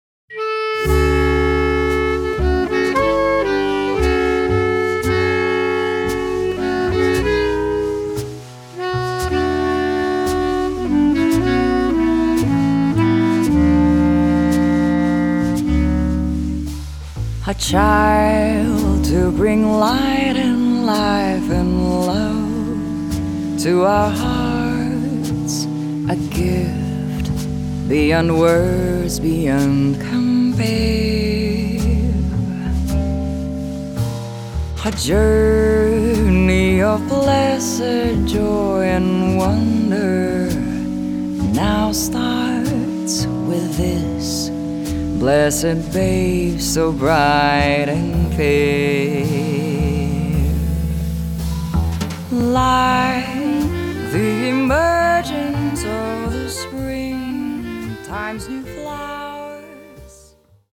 Bass Clarinet
Vibraphone
Double-Bass
Drums
at Skyline Production, South Orange, NJ